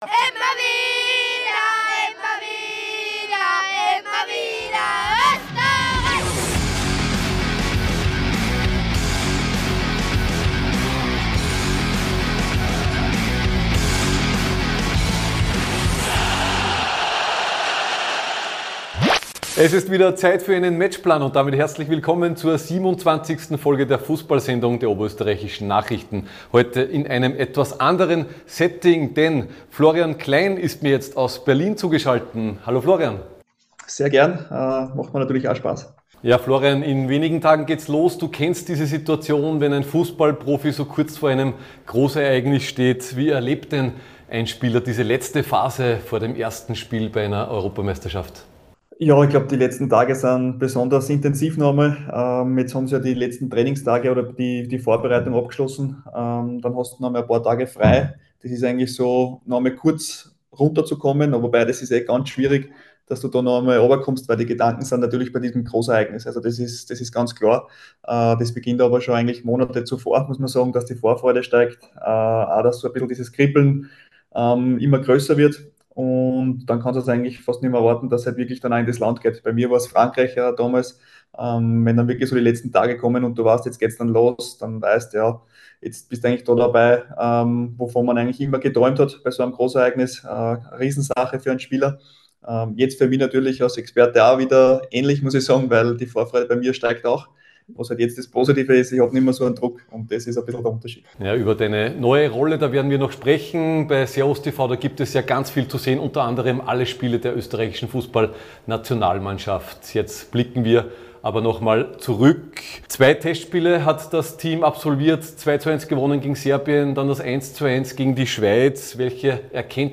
Wenige Tage vor dem Start der Europameisterschaft ist der ehemalige Teamspieler und jetzige TV-Experte Florian Klein zu Gast in der OÖN-Fußballsendung. Für Klein ist die Mannschaft "voll im Plan".